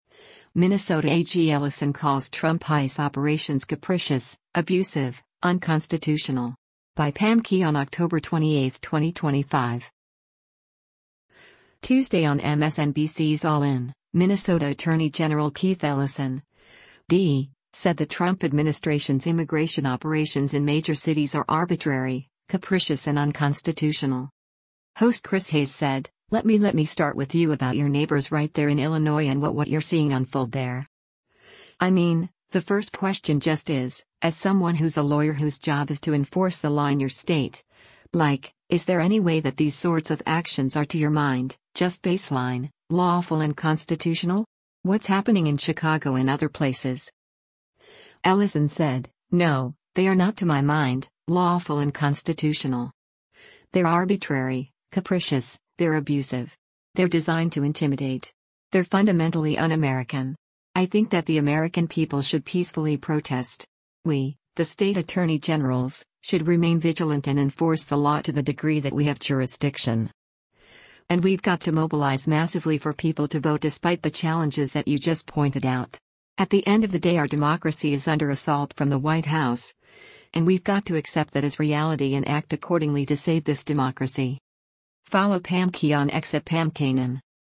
Tuesday on MSNBC’s “All In,” Minnesota Attorney General Keith Ellison (D) said the Trump administration’s immigration operations in major cities are arbitrary, capricious and unconstitutional.